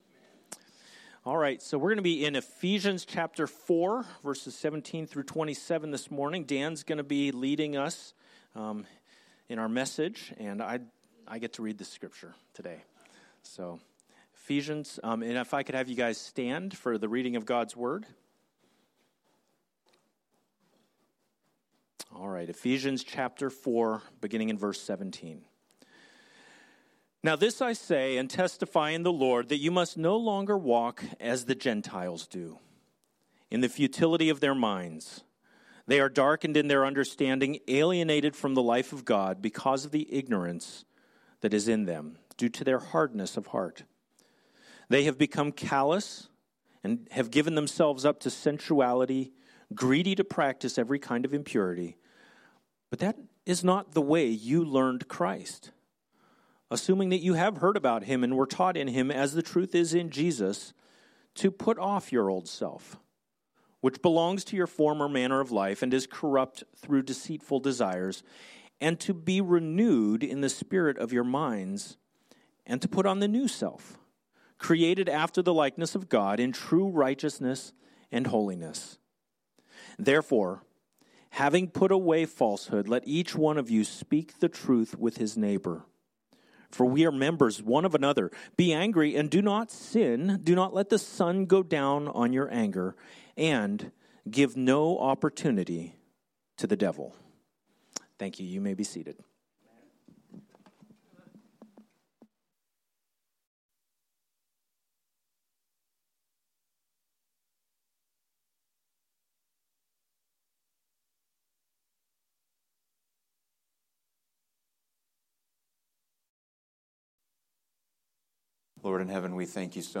Sermons - Grace Church - Pasco